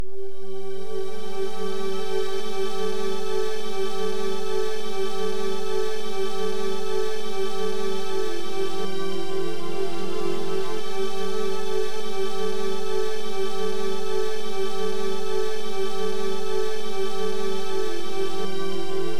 • Synth Symmetrical Texture 100 BPM.wav
Synth_Symmetrical_Texture__100_BPM__7kU.wav